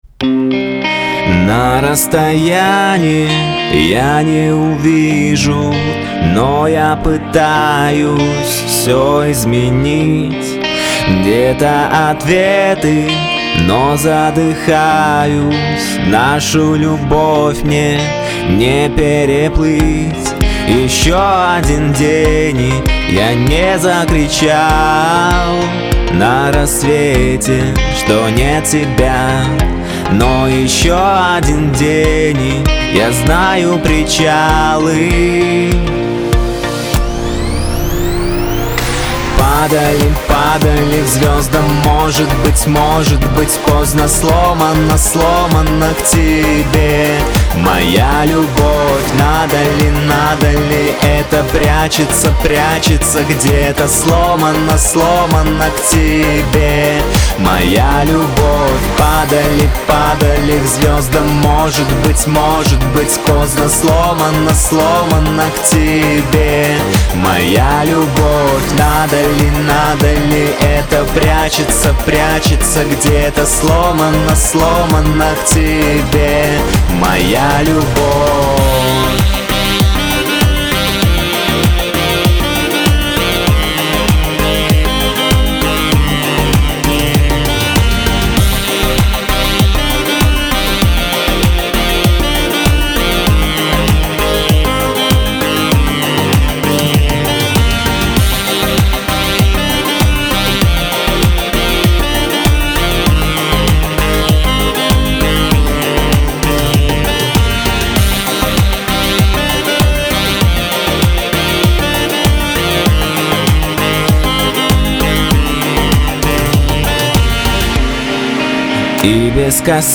Стильно и чувственно.
уверенно, настойчиво и даже вальяжно где тоdada яркая подача
чисто мужской вариант